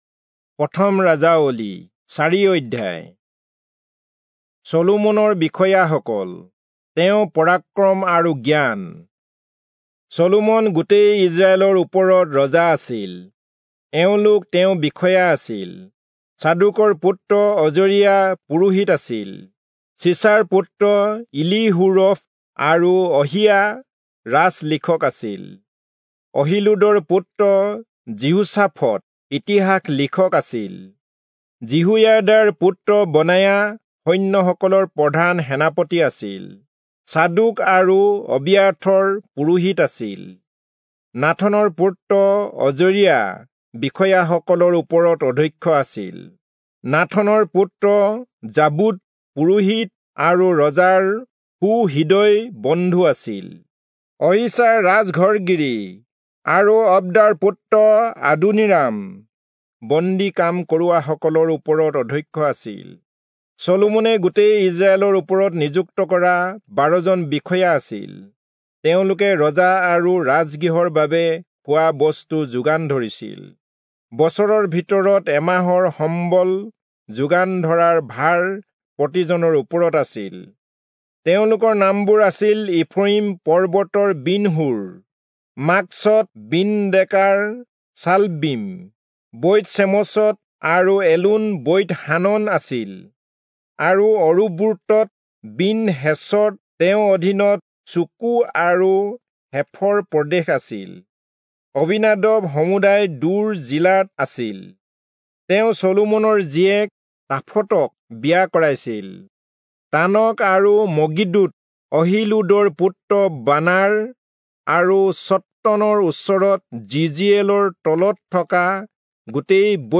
Assamese Audio Bible - 1-Kings 11 in Alep bible version